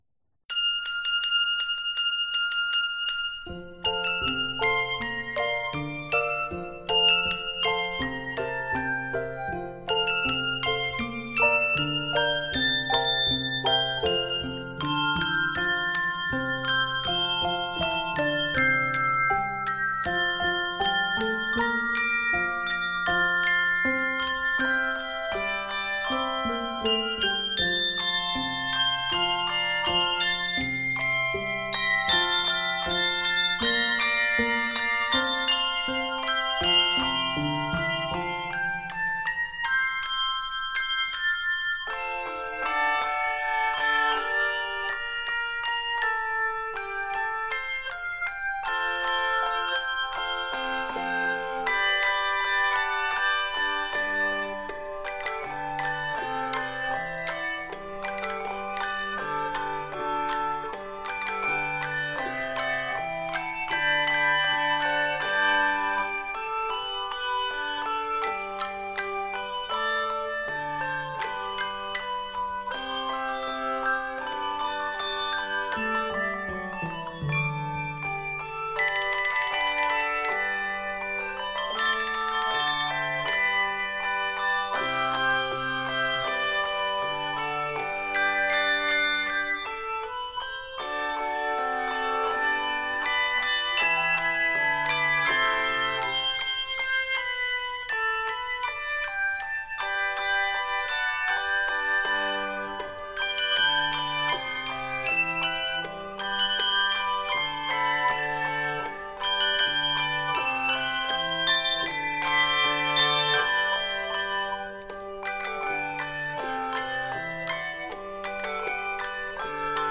Southern gospel energy
Keys of F Major and G Major.